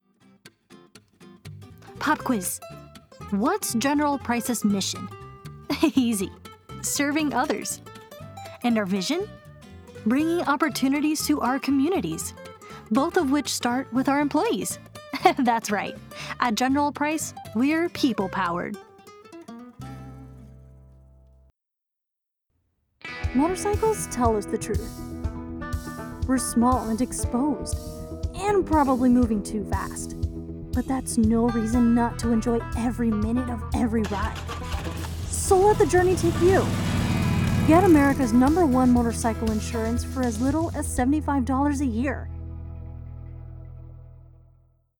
Professional Female Voice Over Talent
a professional female voice talent with boundless creativity